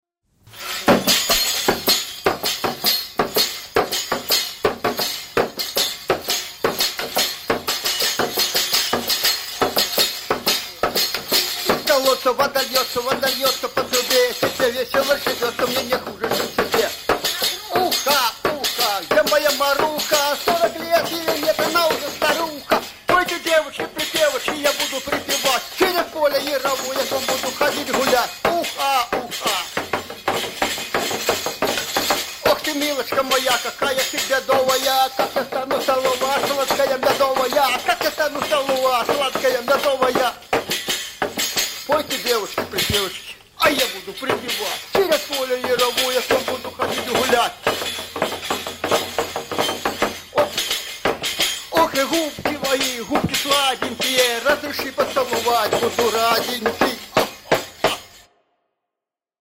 В местных песнях распространен характерный прием гукания — глиссандо голосами (чаще всего восходящее) в окончаниях песенных строк или куплетов.